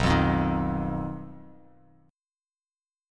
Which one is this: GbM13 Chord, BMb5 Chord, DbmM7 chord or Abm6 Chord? BMb5 Chord